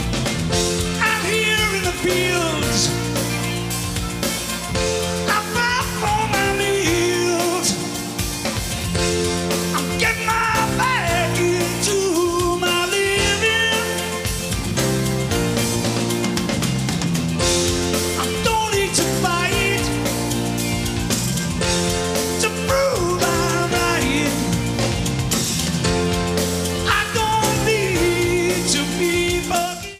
Pre-FM Radio Station Reels